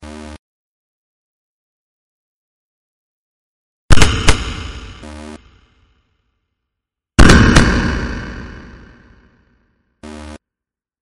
Descarga de Sonidos mp3 Gratis: ruido 4.
rotos_1.mp3